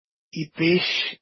Vegetai | Dialetto di Albosaggia